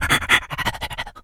dog_sniff_breathe_05.wav